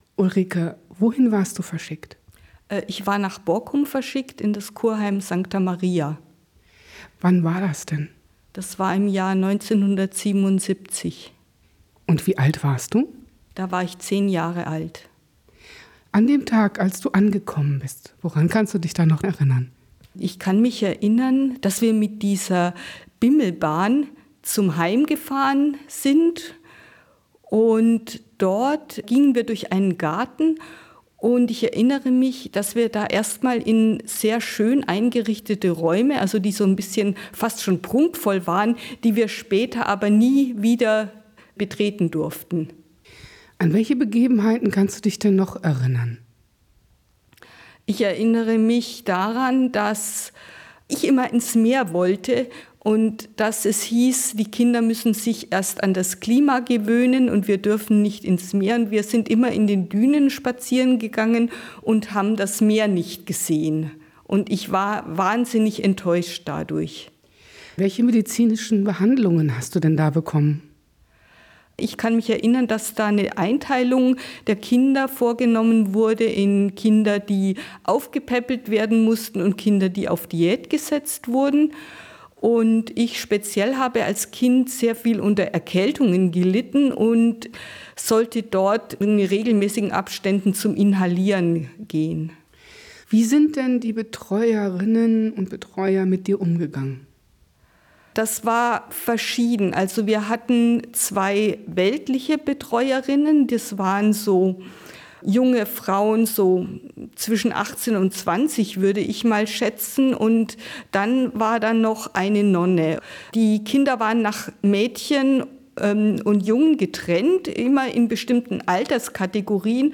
Zeitzeugin_Sancta_Maria_WAV.wav